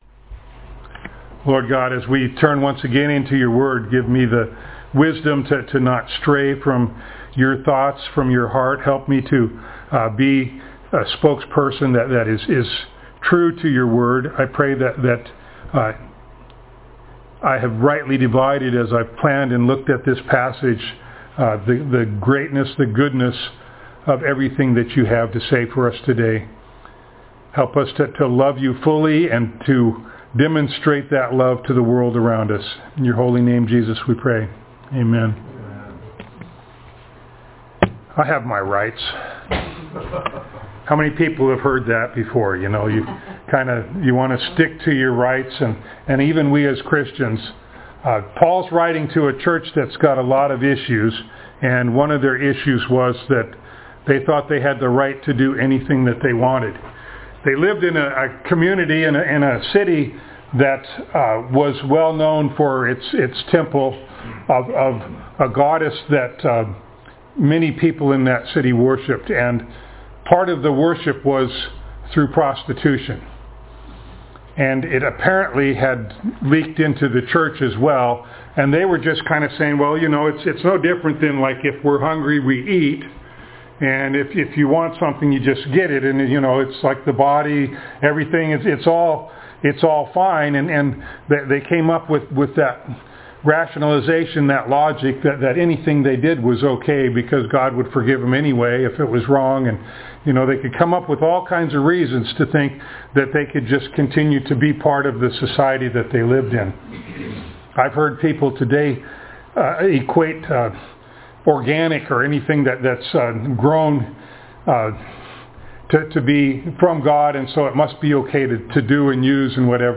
1 Corinthians Passage: 1 Corinthians 6:12-20, Galatians 5:13, Romans 12:1-2, 1 John 3:16-18 Service Type: Sunday Morning